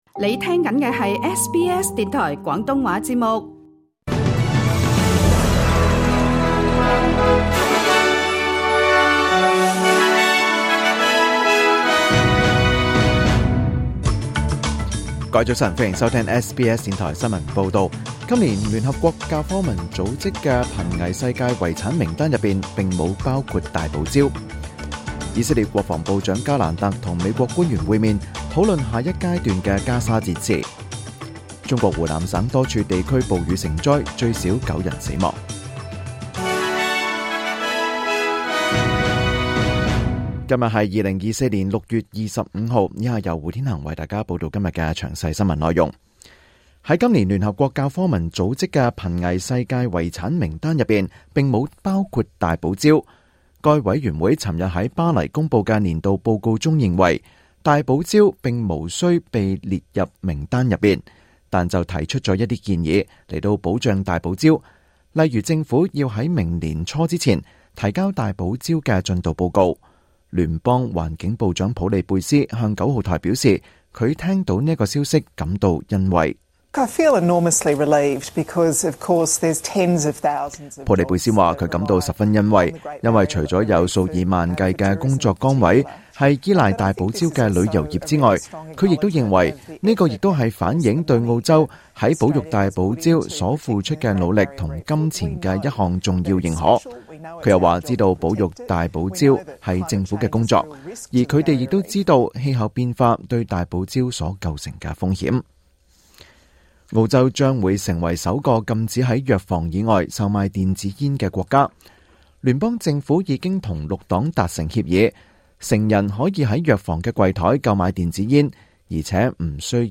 2024年6月25日SBS廣東話節目詳盡早晨新聞報道。